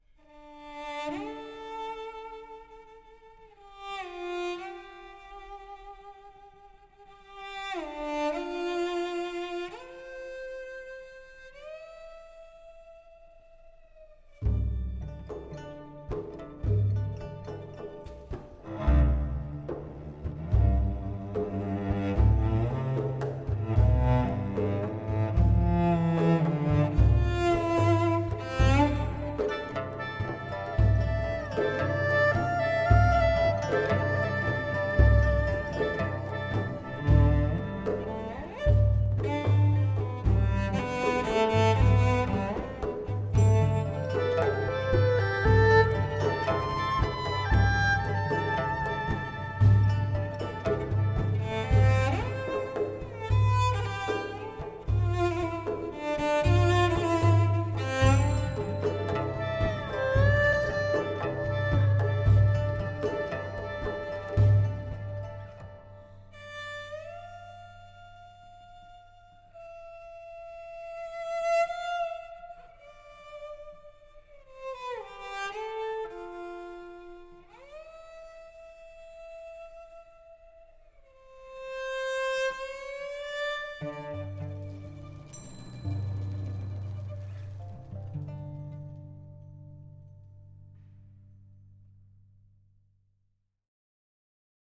Music from the film